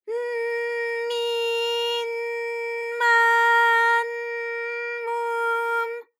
ALYS-DB-001-JPN - First Japanese UTAU vocal library of ALYS.
m_n_mi_n_ma_n_mu_m.wav